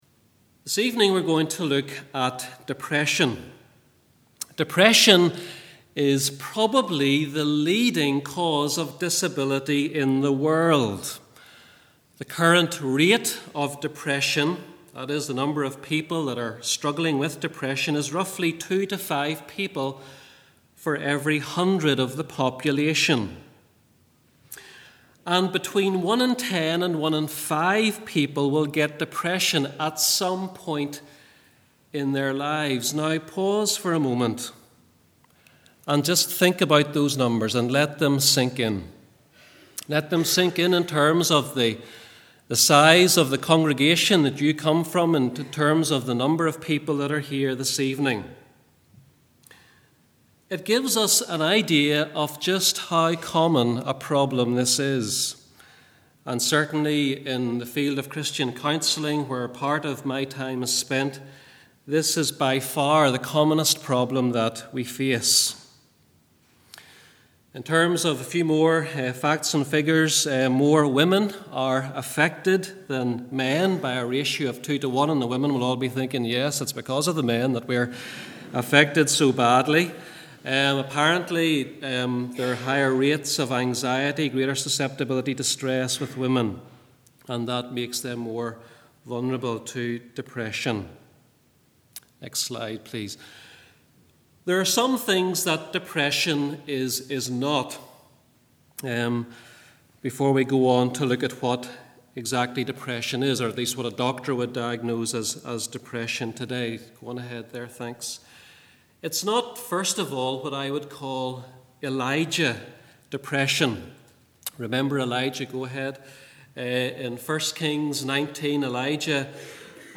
Recorded in St Colmanell's Church of Ireland.